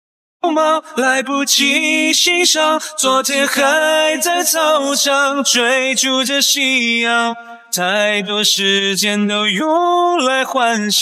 Autentisk Berättarröst för Skolberättelser
Fängsla elever och lyssnare med en varm, relaterbar AI-röst designad för pedagogiska berättelser, ljudböcker och klassrumsberättande.
Text-till-tal
Ungdomlig Ton
Vår AI levererar en genuin, elevliknande ton som fångar essensen av skolliv och uppväxtberättelser. Med subtila variationer i tonhöjd och pauser efterliknar den den naturliga kadensen hos en ung berättare.